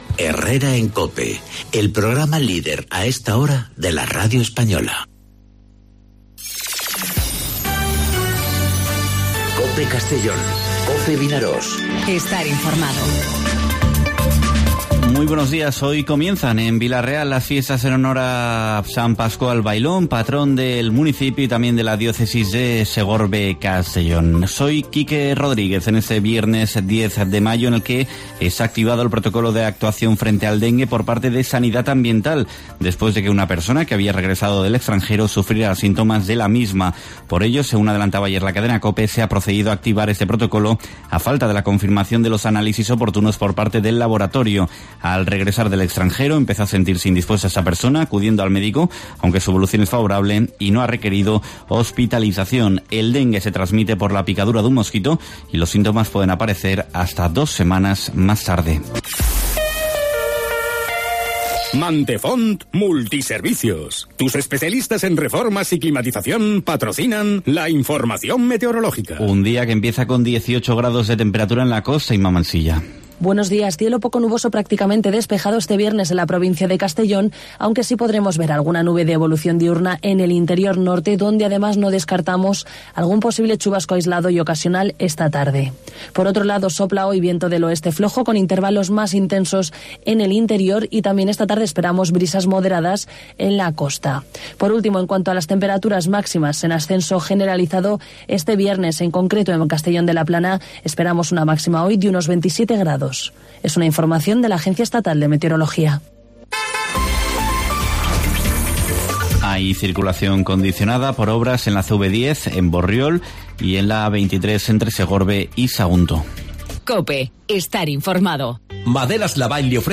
Informativo 'Herrera en COPE' Castellón (10/05/2019)